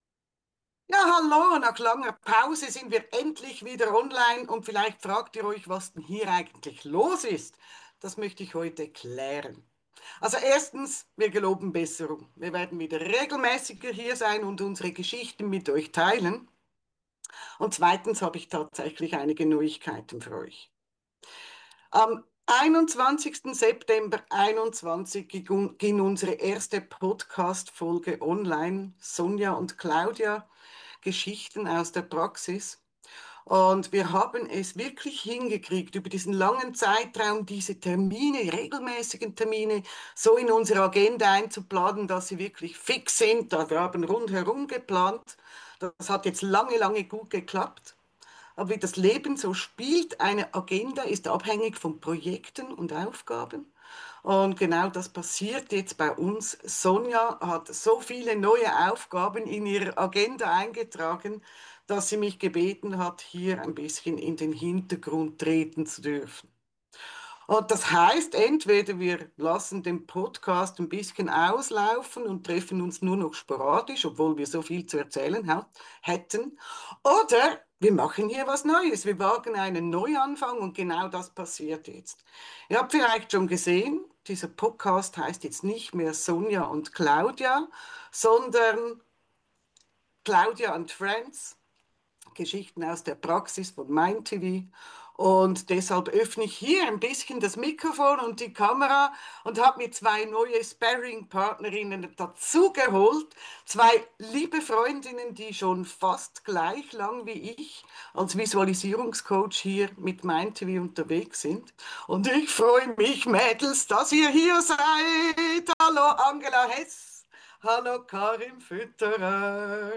Der spontane mindTV Wochentalk